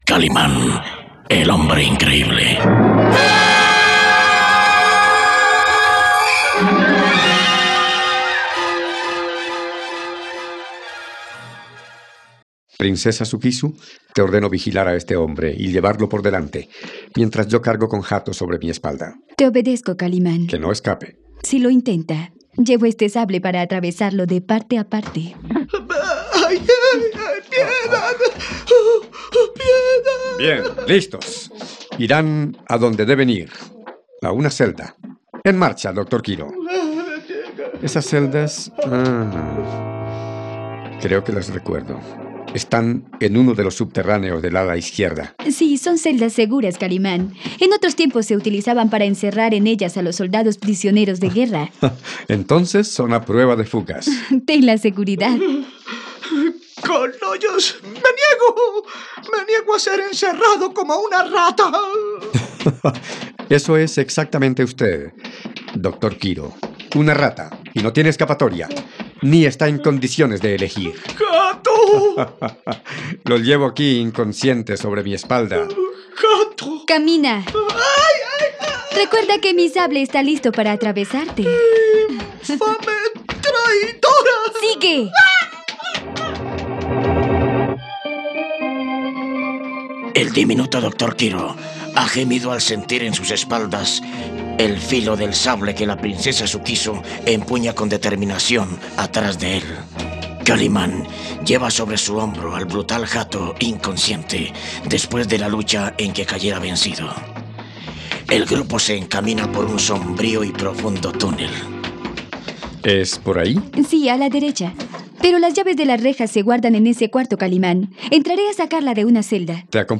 radionovela